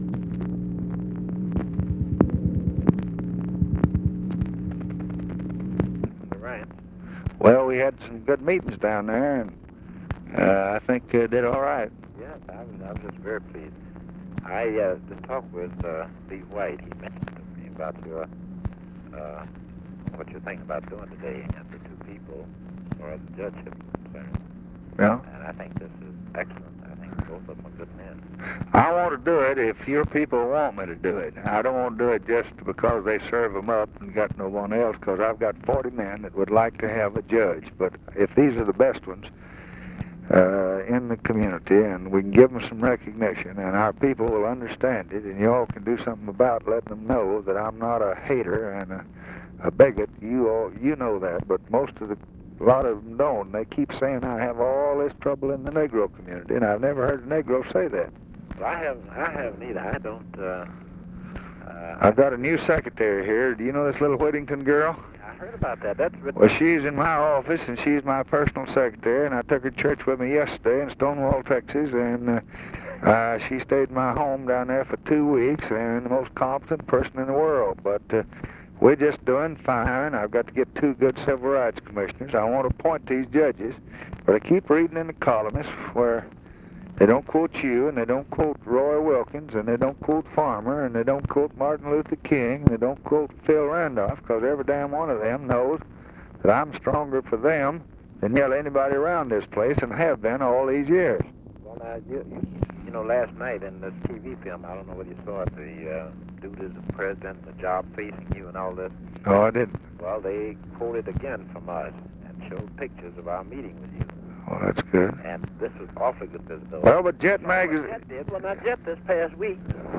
Conversation with WHITNEY YOUNG, January 6, 1964
Secret White House Tapes | Lyndon B. Johnson Presidency